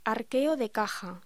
Locución: Arqueo de caja